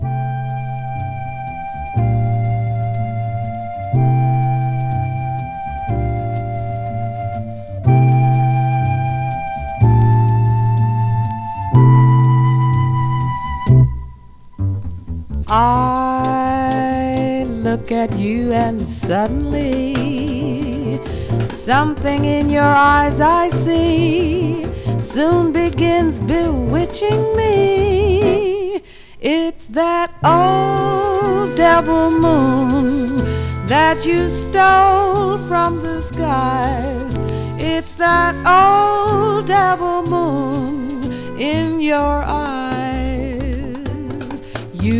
Jazz, Bop, Big Band　USA　12inchレコード　33rpm　Mono